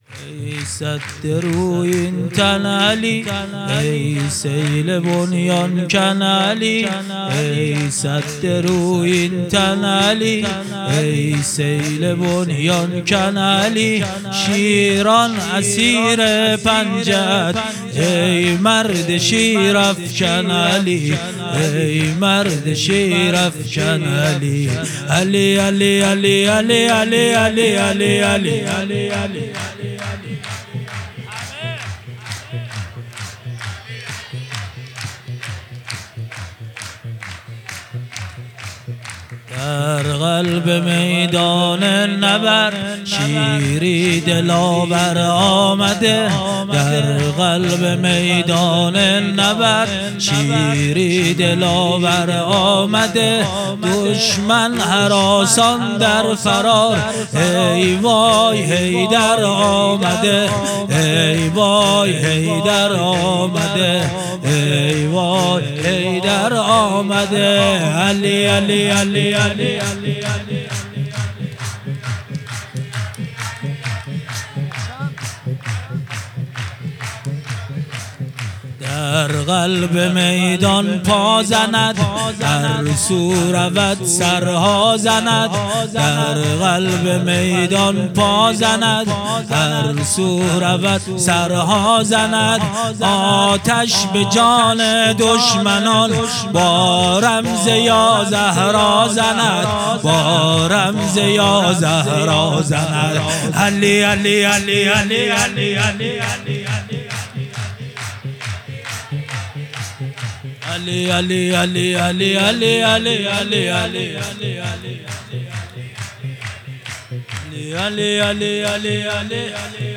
هیئت محبان الحسین علیه السلام مسگرآباد